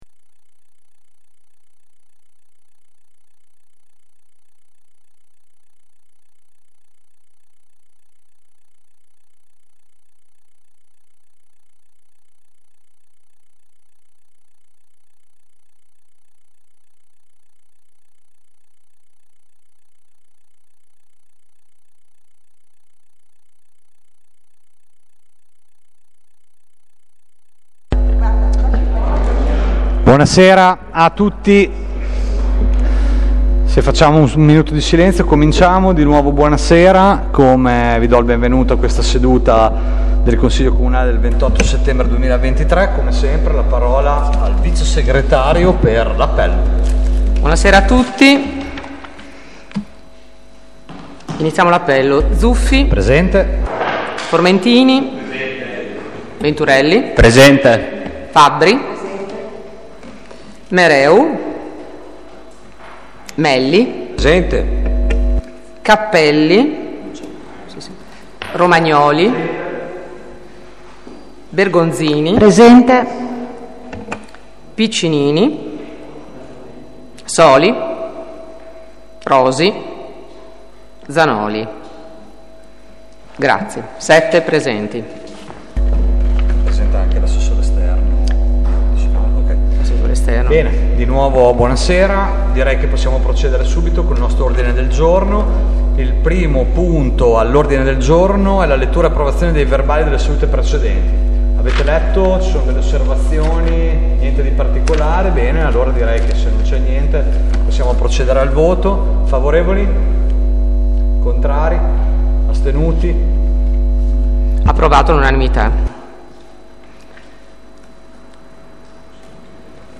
Consiglio Comunale del 28 Settembre 2023